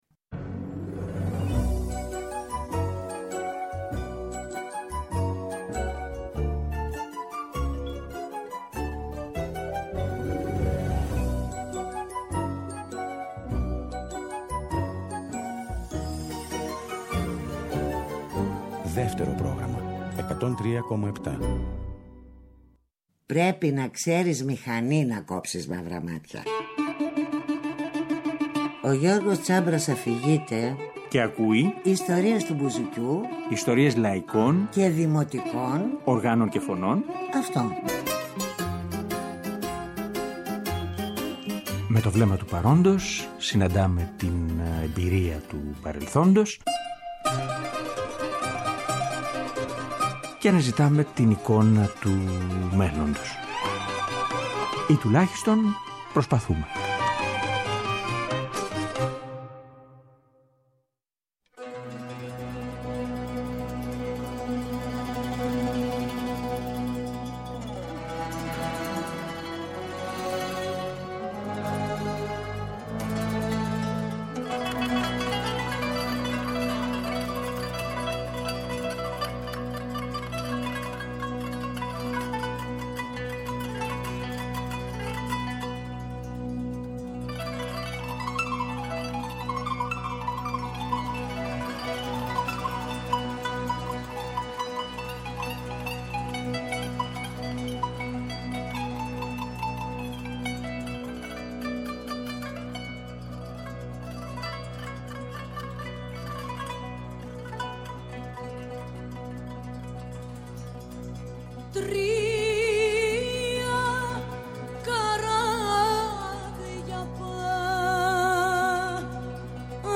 στο Μέγαρο Μουσικής
Σύριου ουτίστα και τραγουδιστή